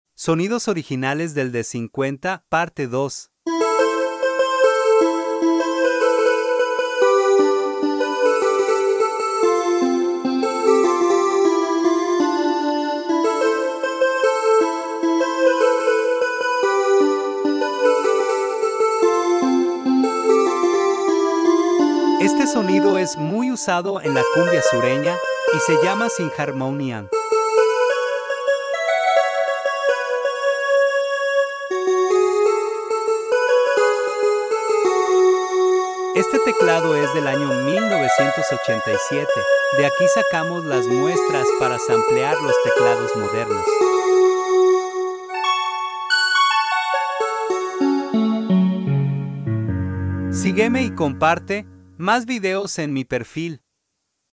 Sonidos nativos del Roland D50🔥 sound effects free download